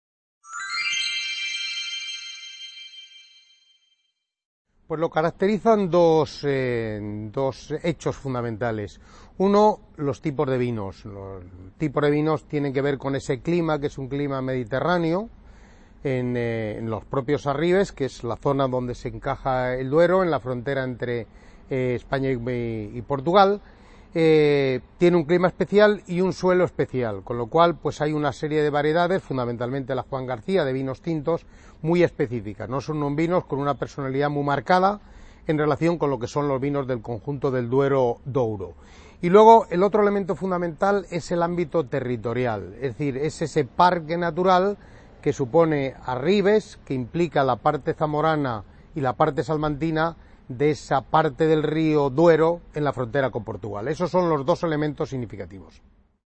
C.A. Ponferrada - II Congreso Territorial del Noroeste Ibérico